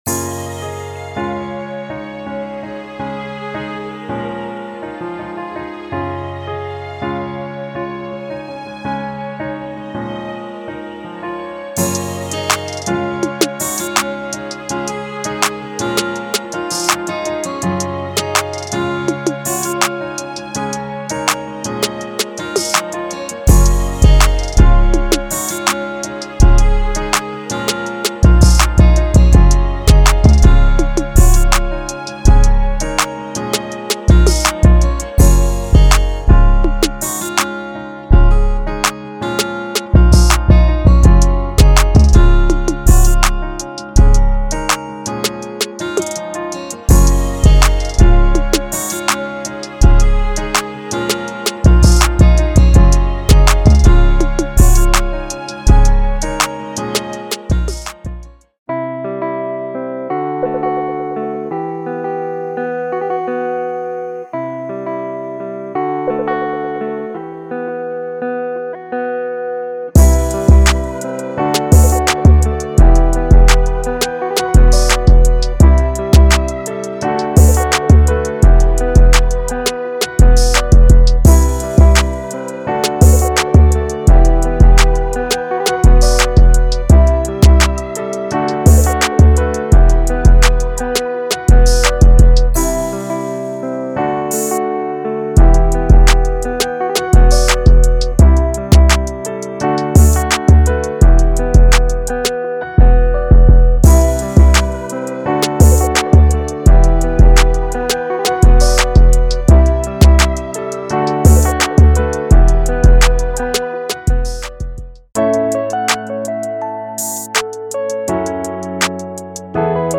authentic worship music with captivating melodies